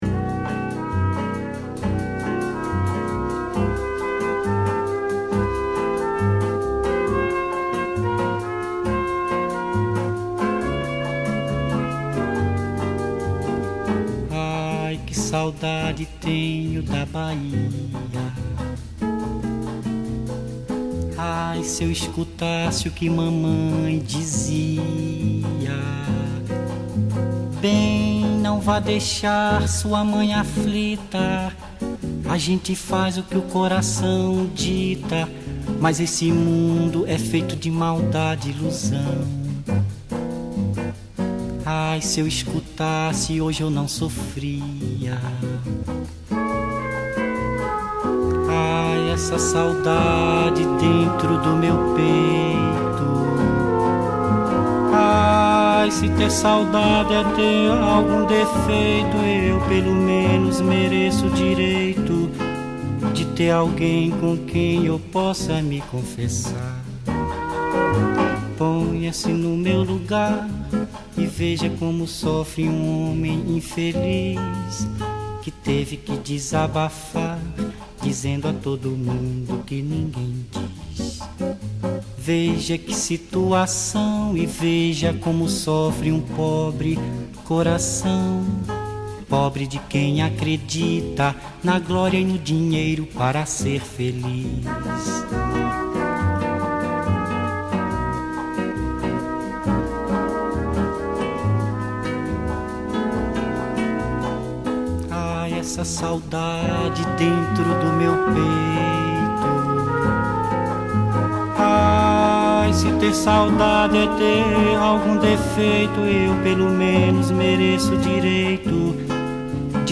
música popular brasileira – MPB